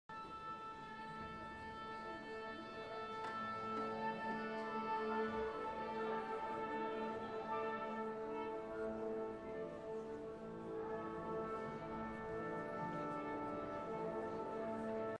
Stimovanje.mp3